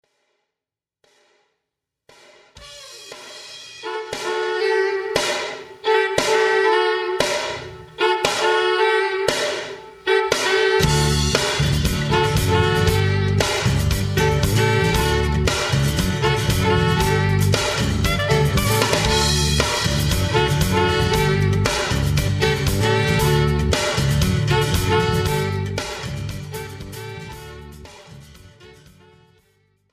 This is an instrumental backing track cover.
Key – D
Without Backing Vocals
No Fade